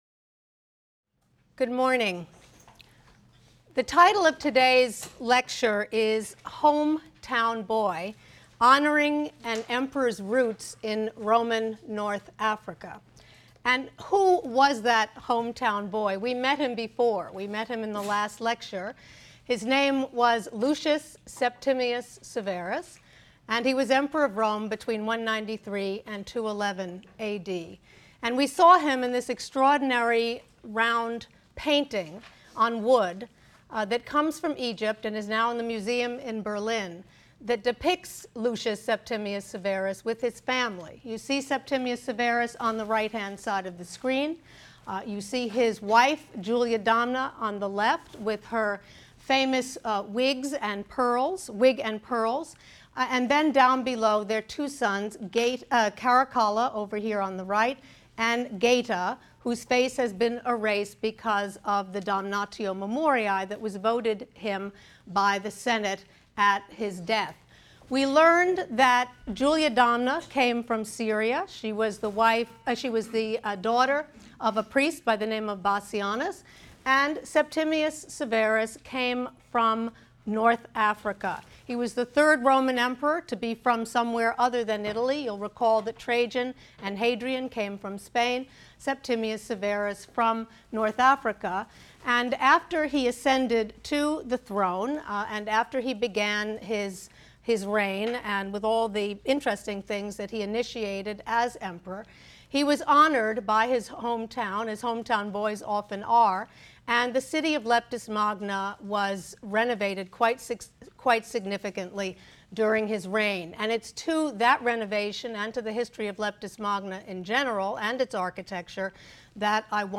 HSAR 252 - Lecture 18 - Hometown Boy: Honoring an Emperor’s Roots in Roman North Africa | Open Yale Courses